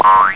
Boing.mp3